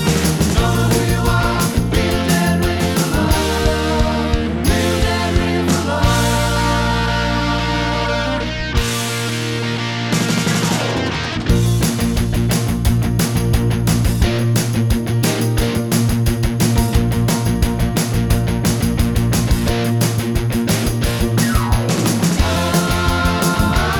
One Semitone Down Rock 4:14 Buy £1.50